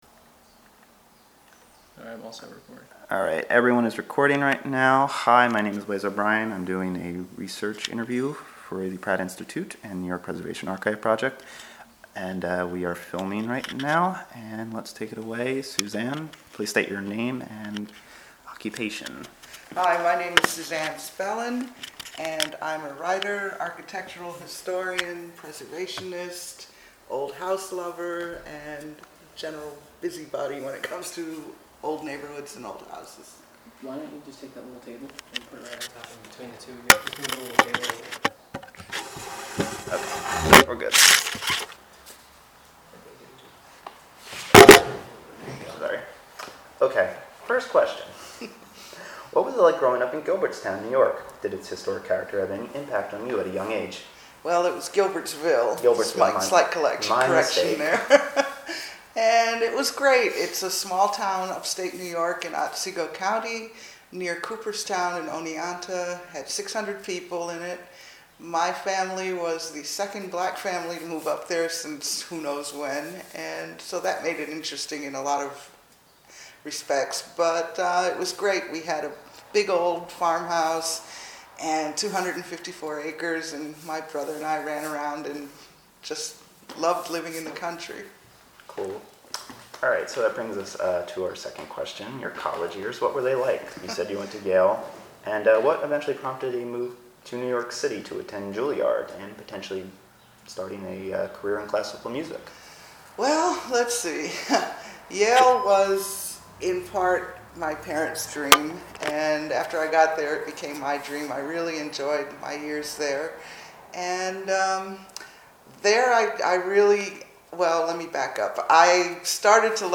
I’m doing a research interview for the Pratt Institute and the New York Preservation Archive Project.